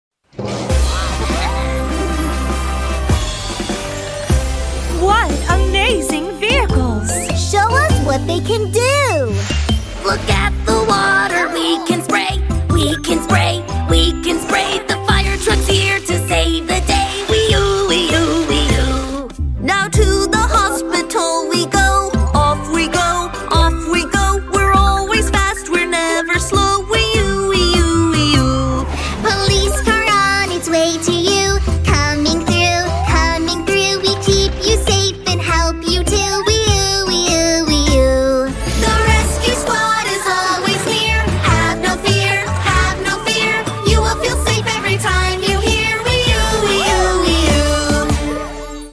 " is full of fun and energy